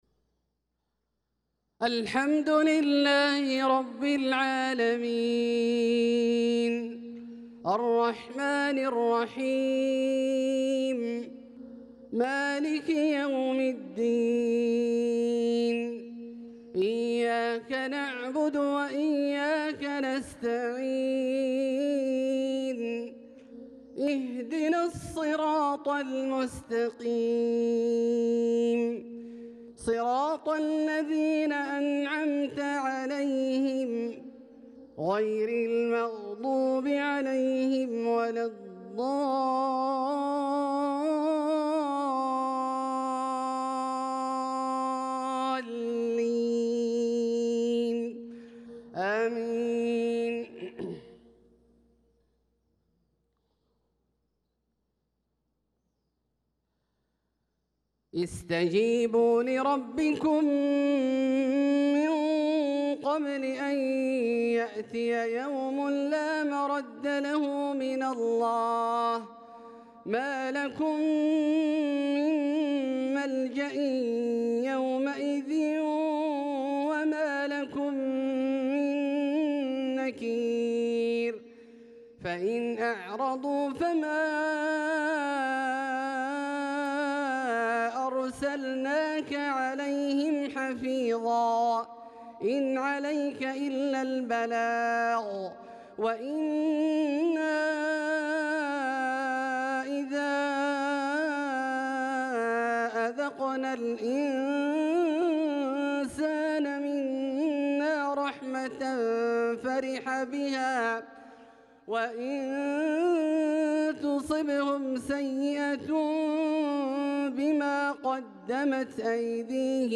صلاة المغرب للقارئ عبدالله البعيجان 26 ذو القعدة 1445 هـ
تِلَاوَات الْحَرَمَيْن .